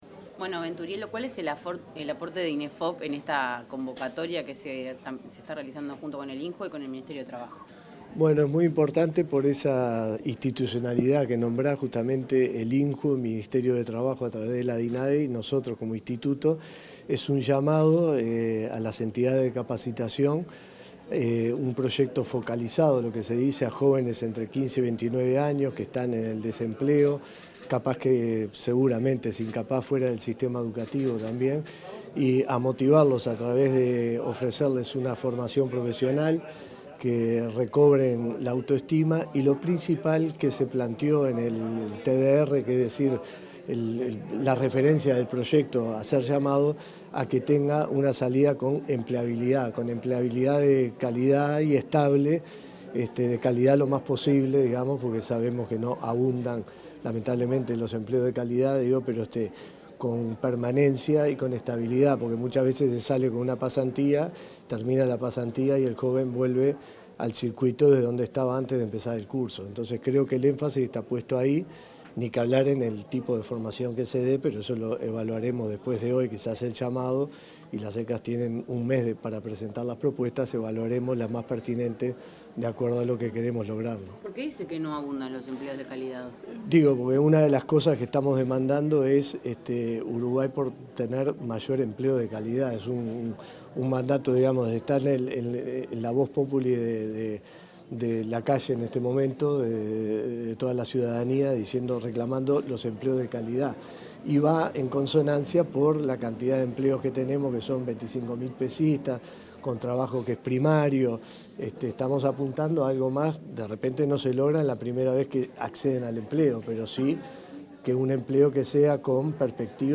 Declaraciones del director general del Inefop, Miguel Venturiello
Declaraciones del director general del Inefop, Miguel Venturiello 15/09/2025 Compartir Facebook X Copiar enlace WhatsApp LinkedIn En el marco del lanzamiento de una convocatoria de formación profesional, el director general del Instituto Nacional de Empleo y Formación Profesional (Inefop), Miguel Venturiello, dialogó con la prensa.